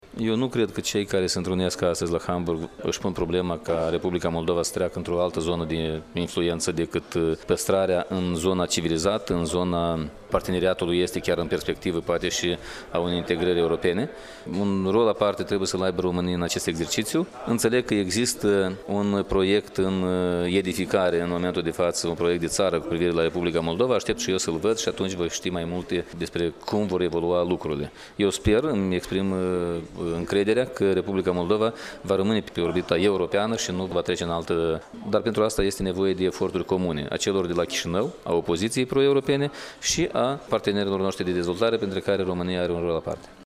Declaraţia a fost făcută astăzi la Iaşi, iar Andrei Năstase a mai spus că, în cadrul acestui parteneriat estic, România va avea un statut special, pentru a păstra Republica Moldova pe orbita pro-europeană.
Liderul platformei civice Demnitate şi Adevăr a mai declarat că aşteaptă să vadă prevederile viitorului proiect de ţară pe care Bucureştiul doreşte să-l elaboreze faţă de Republica Moldova: